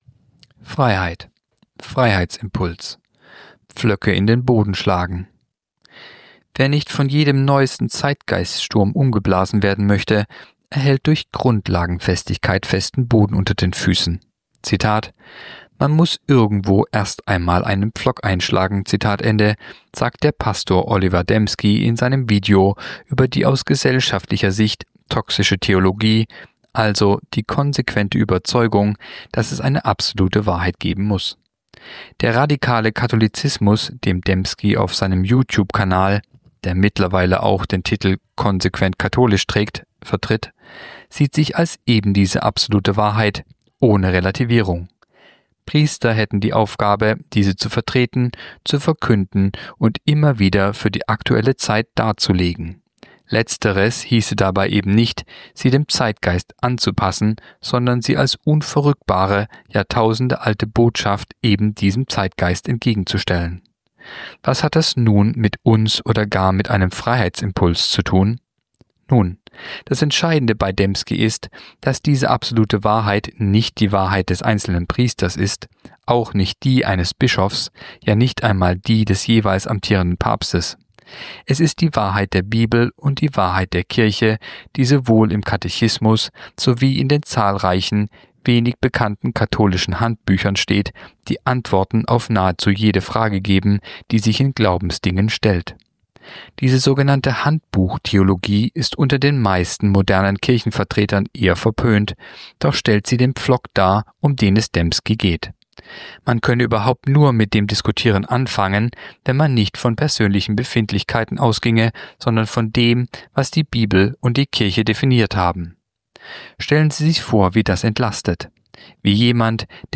Kolumne der Woche (Radio)Freiheitsimpuls
(Sprecher)